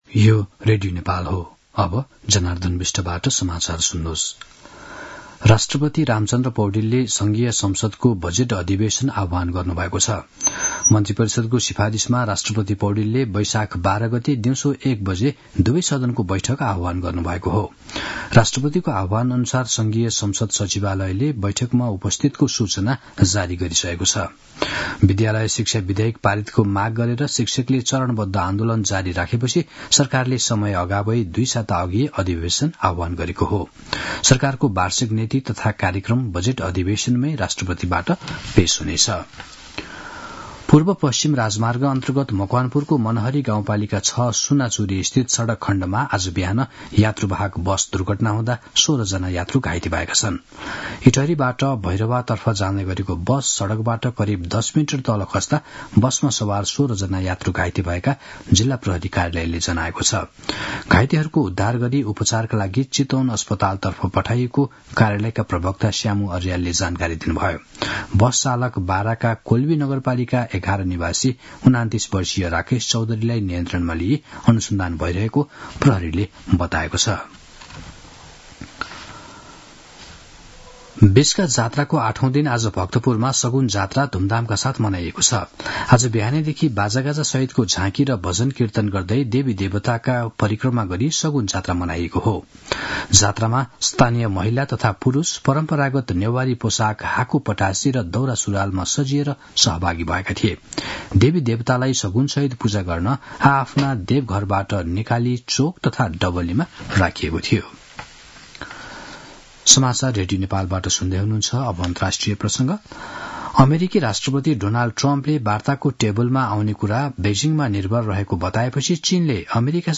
मध्यान्ह १२ बजेको नेपाली समाचार : ४ वैशाख , २०८२